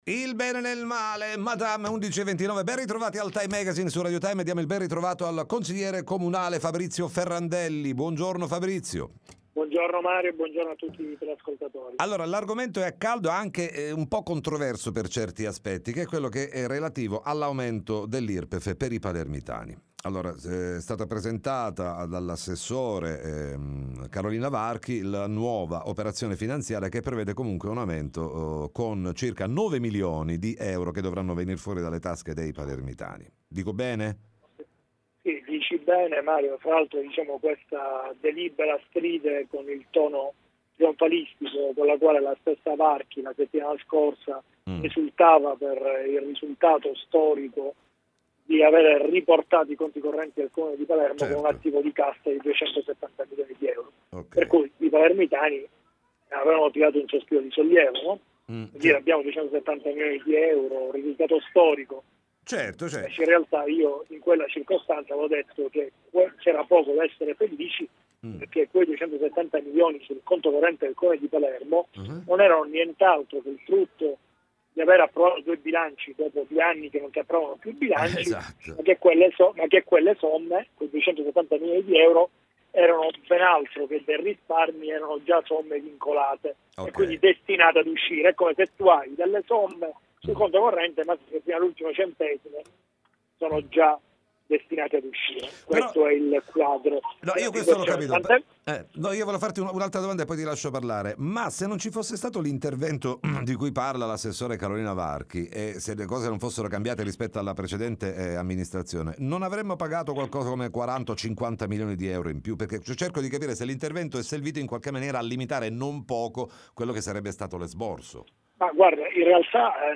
TM Intervista Fabrizio Ferrandelli